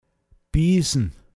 pinzgauer mundart
rasen, blind dahinstürmen (Vieh bei Bremsenbefall) piisn